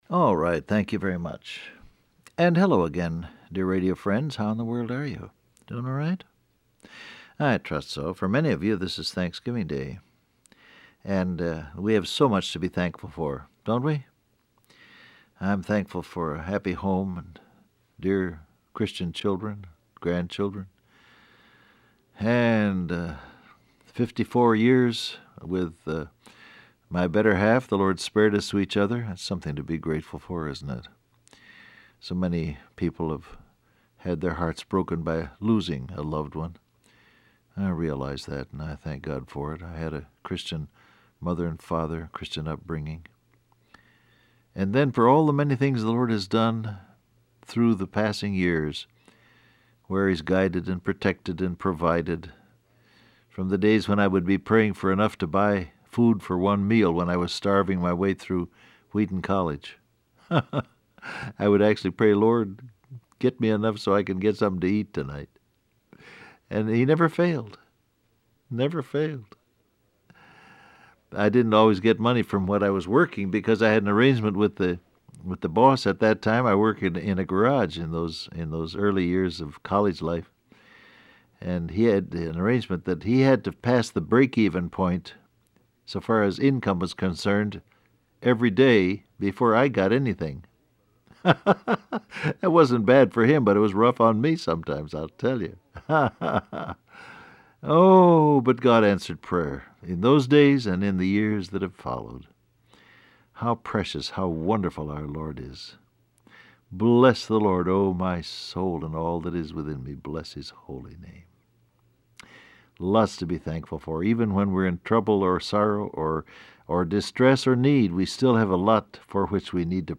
Download Audio Print Broadcast #7167 Scripture: 1 Peter 3:9-12 Topics: Earnest Prayer , Answered Prayer , Prayers , God Hears , Casual Prayers Transcript Facebook Twitter WhatsApp Alright, thank you very much.